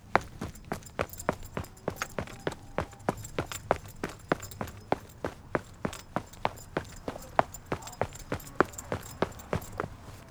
running-footsteps-on-pavement.wav
Footsteps